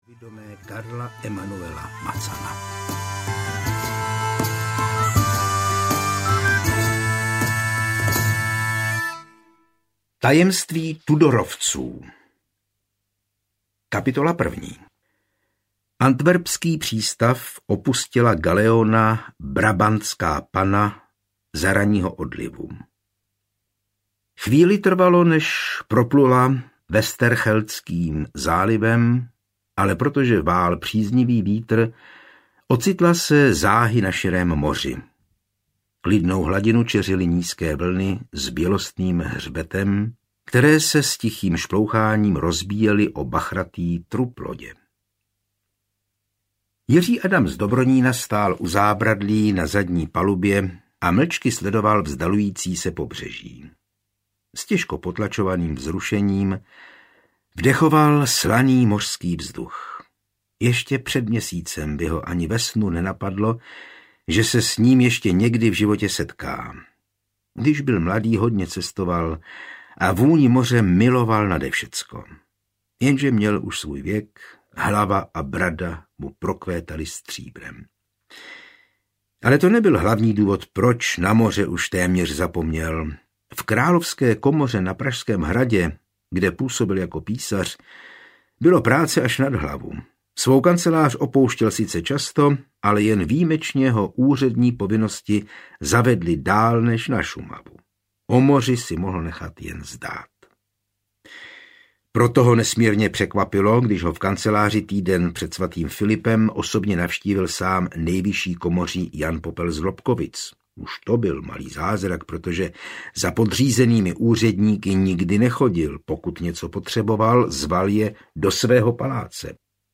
Tajemství Tudorovců audiokniha
Ukázka z knihy